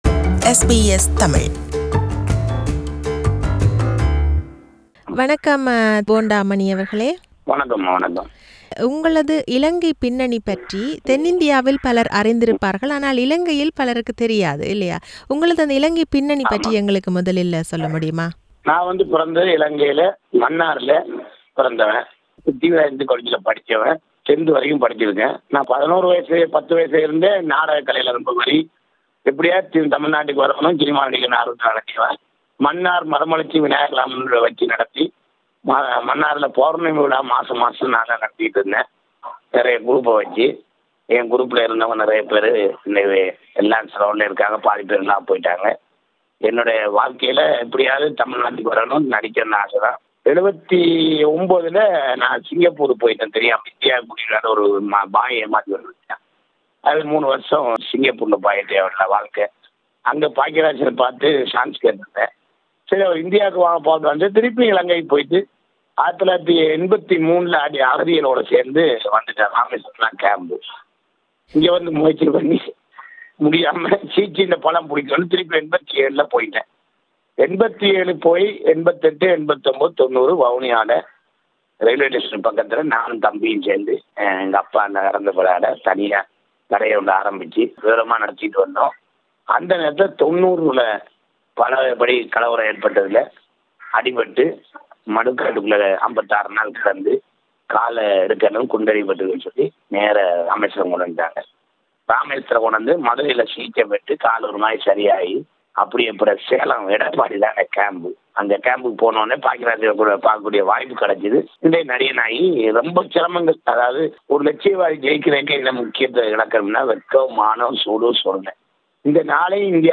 An interview with Comedian Bonda Mani!
This is an interview with him.